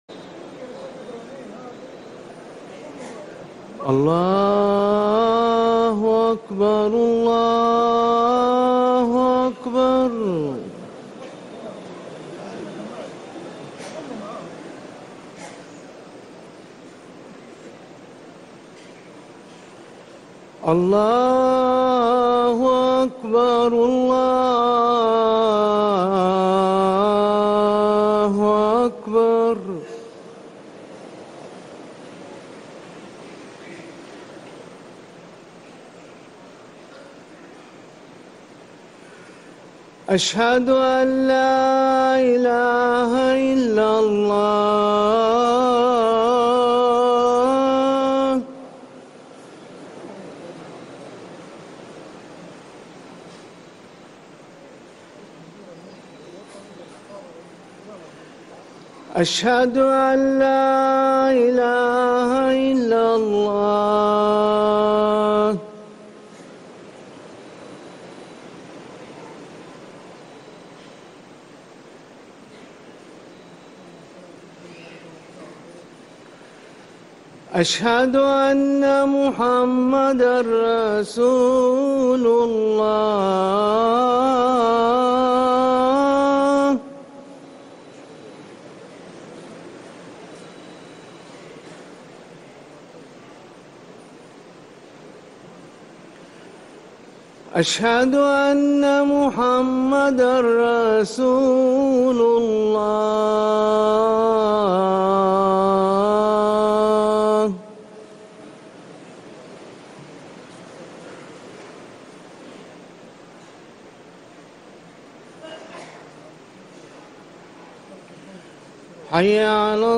أذان العشاء